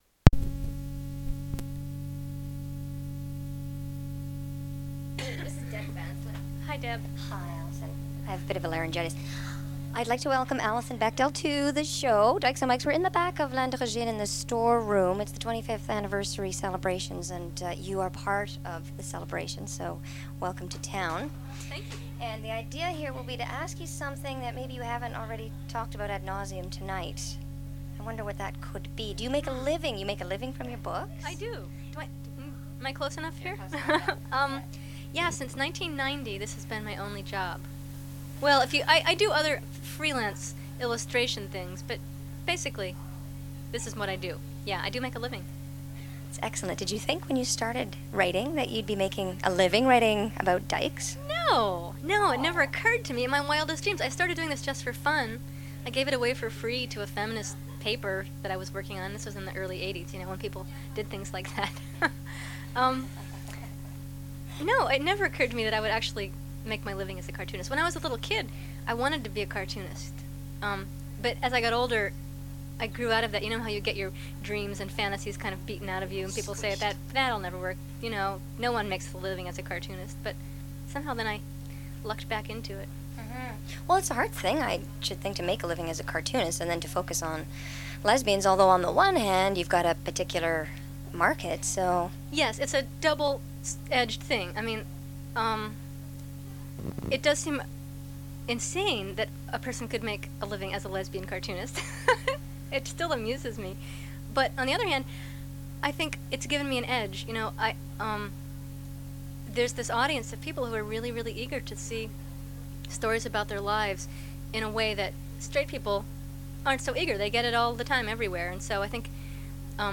Recording of Dykes on Mykes on September 12, 1998 with guest Alison Bechdel, American cartoonist. They discuss Bechdel’s work as a cartoonist and lesbian representation in art and media.
The Dykes on Mykes radio show was established in 1987.